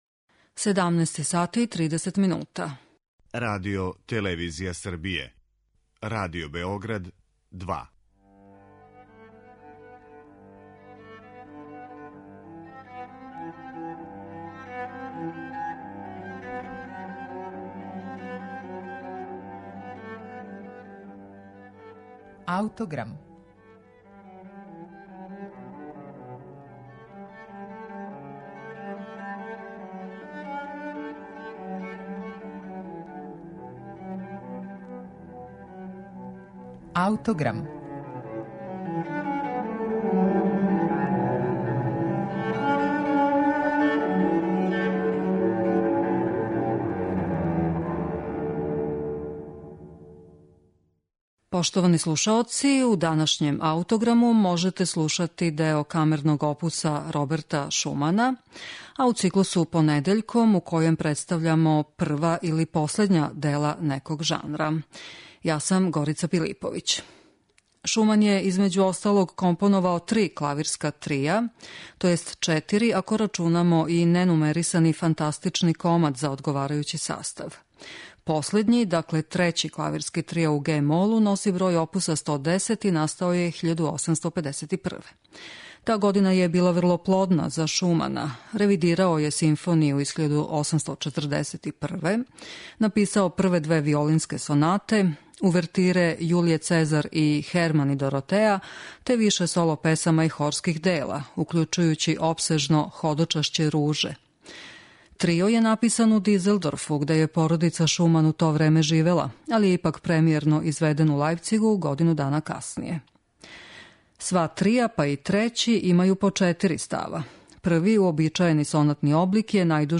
Слушаћете Шуманов клавирски трио, оп. 110